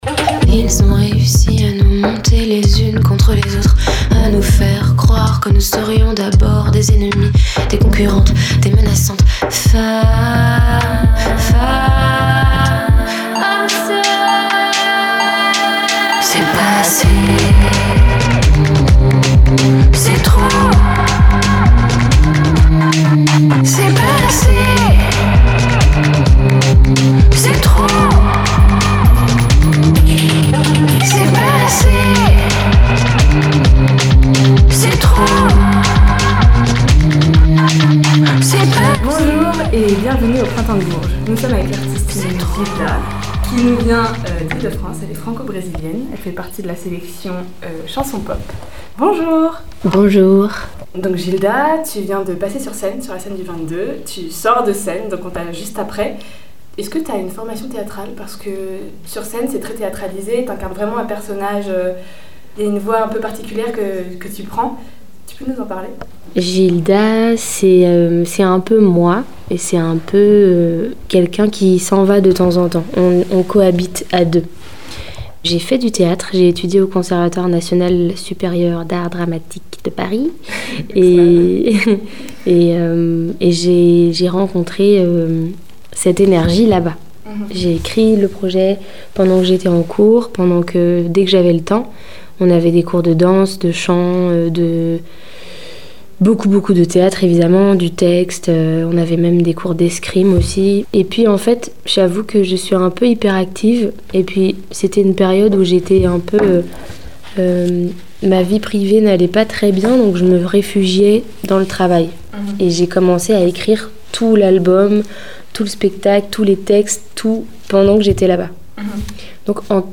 en direct du Printemps de Bourges en podcast